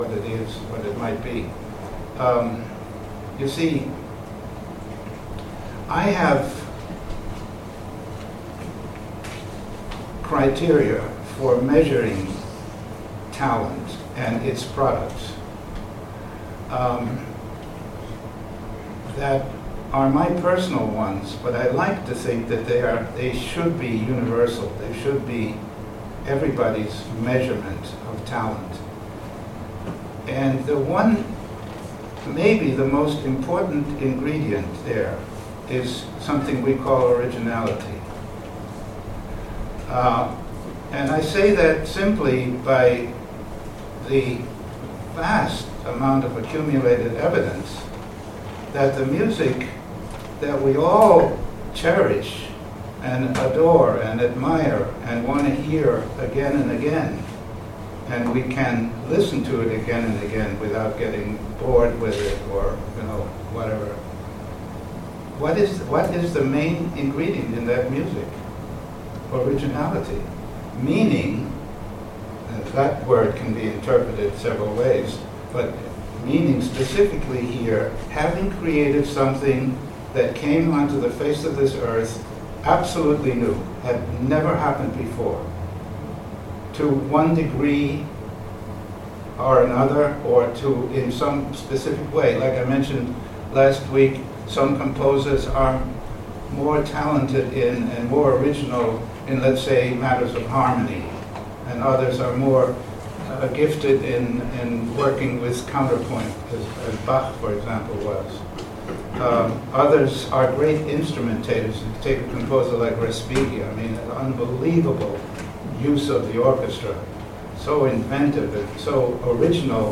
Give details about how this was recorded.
Next, I have some brief excerpts recorded from lectures he gave during his residency at UW-Madison (mentioned in the above quote) in fall 2005. • “Talent and Originality,” The Creative Process Course, September 14, 2005